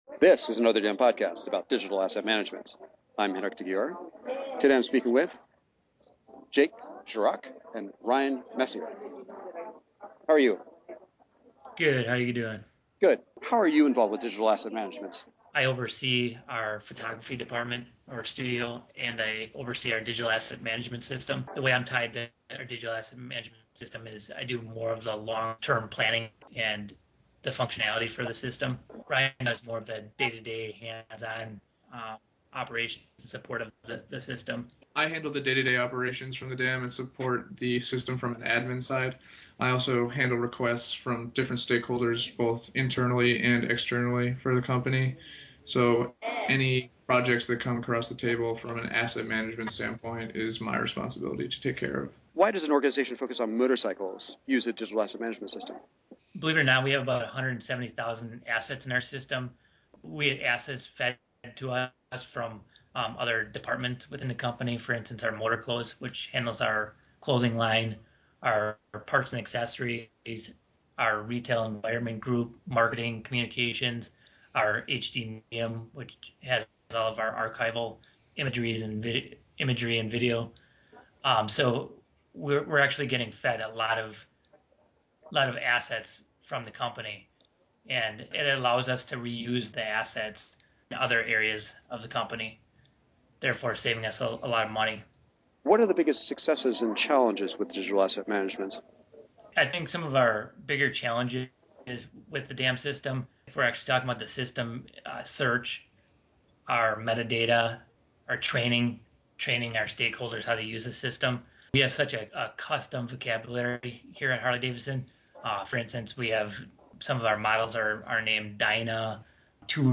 Here are the questions asked: